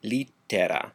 Ääntäminen
US : IPA : [ˈlɛt.ɚ]